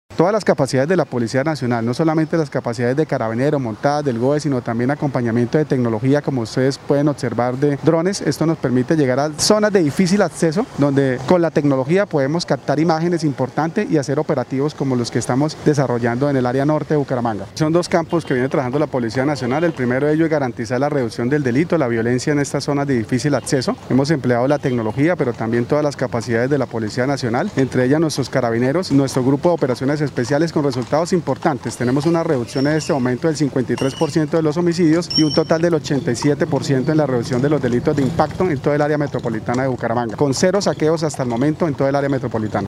Luis Ernesto García, comandante de la Mebuc